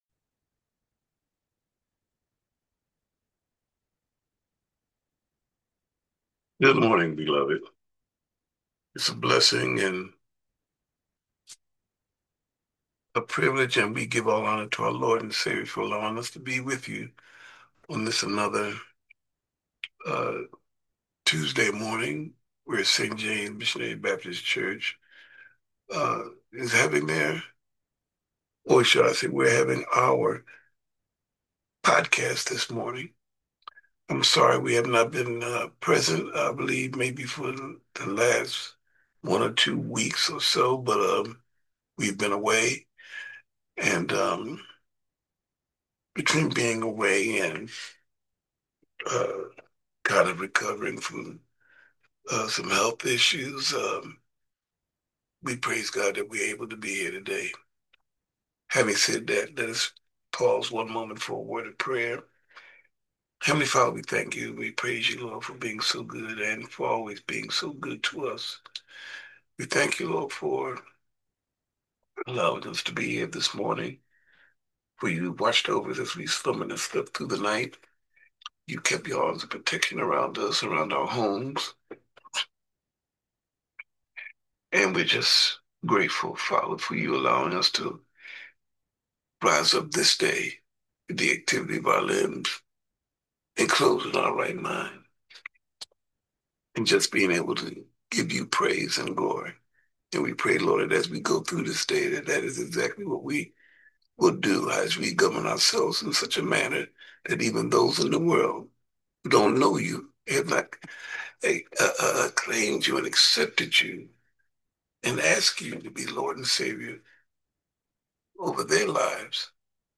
Remember, our ZOOM Podcast airs live on Tuesdays at 7:00am: